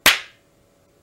Pac Man Slap - Botón de Efecto Sonoro